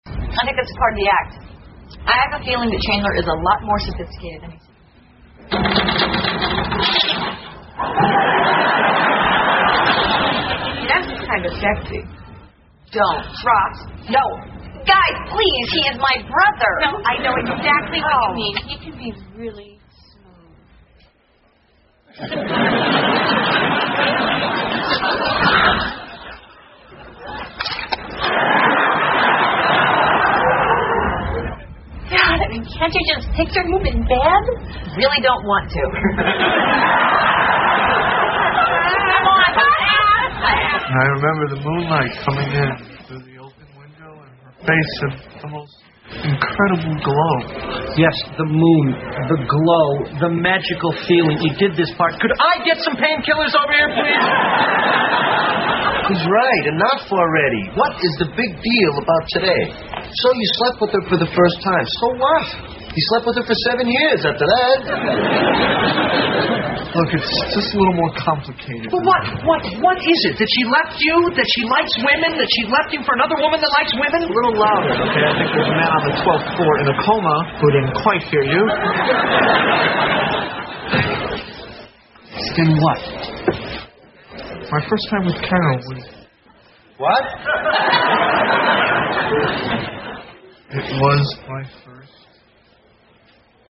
在线英语听力室老友记精校版第1季 第44期:克林顿亲信助手(12)的听力文件下载, 《老友记精校版》是美国乃至全世界最受欢迎的情景喜剧，一共拍摄了10季，以其幽默的对白和与现实生活的贴近吸引了无数的观众，精校版栏目搭配高音质音频与同步双语字幕，是练习提升英语听力水平，积累英语知识的好帮手。